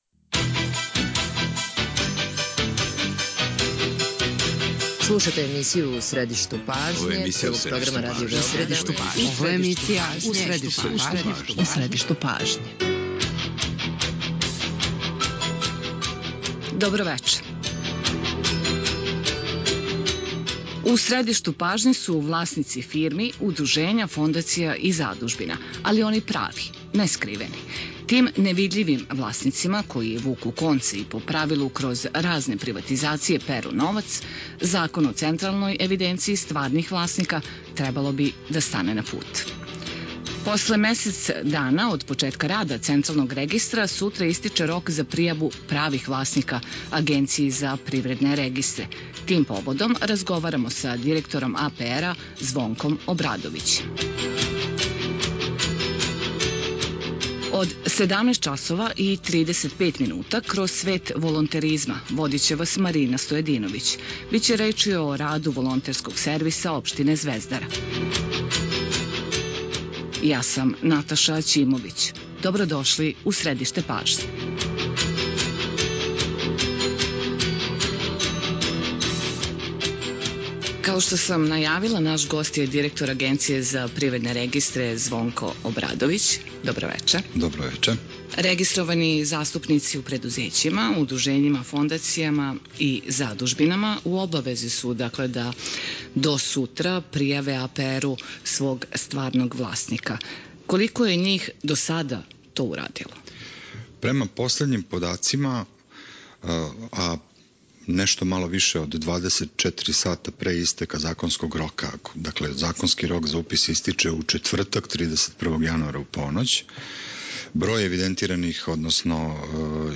Гост емисије је директор АПР-а Звонко Обрадовић.